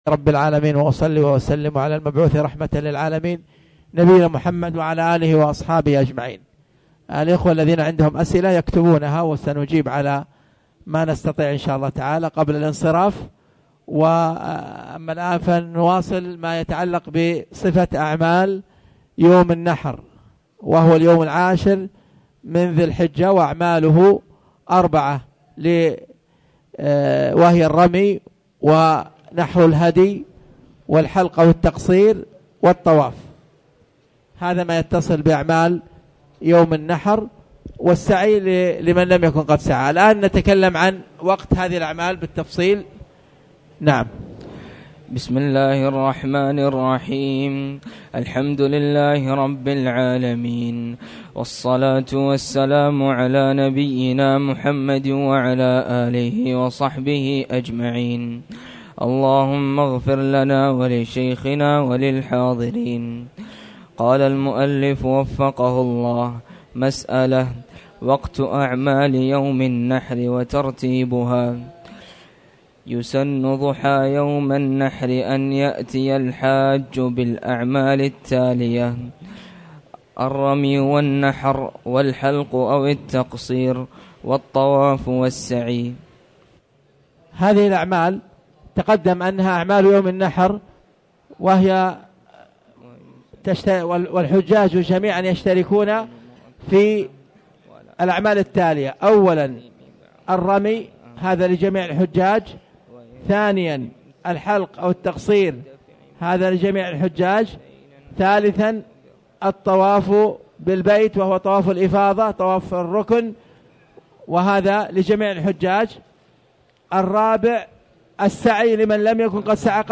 تاريخ النشر ٧ ذو الحجة ١٤٣٨ هـ المكان: المسجد الحرام الشيخ